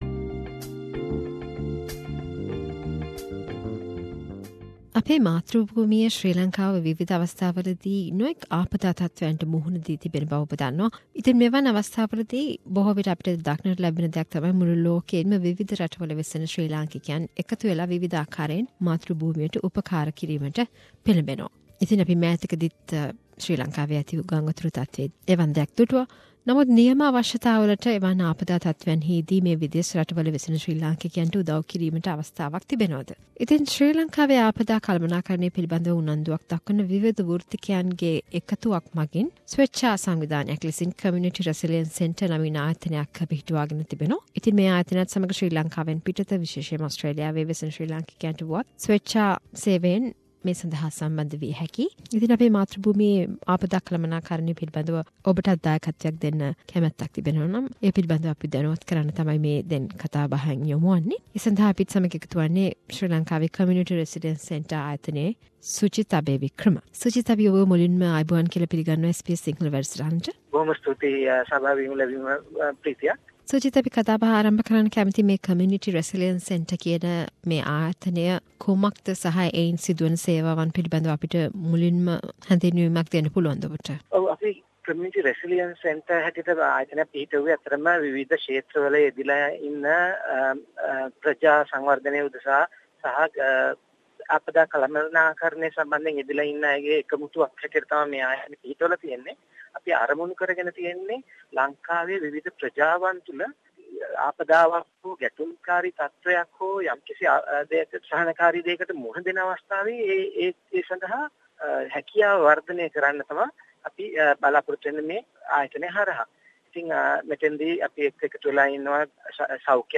A discussion with Community resilience centre